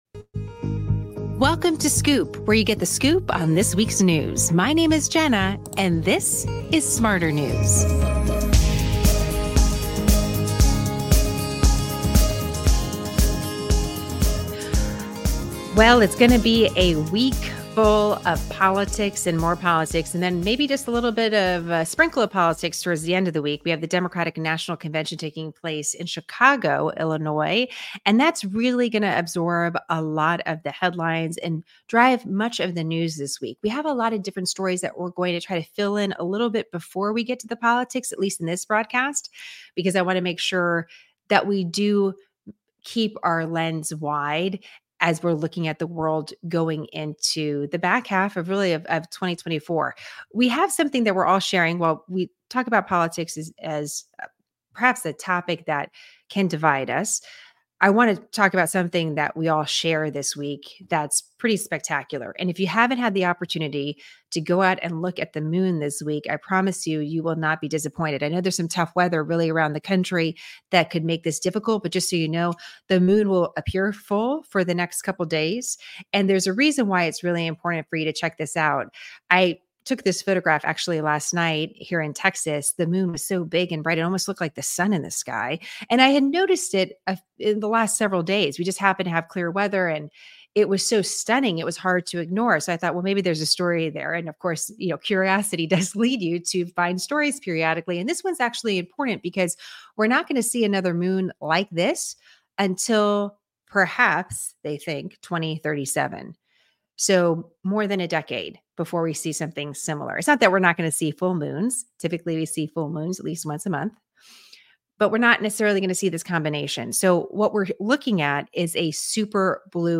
✓ 27:00: Soundbites from two political operatives on two different sides of the political spectrum: David Axelrod and Kellyanne Conway.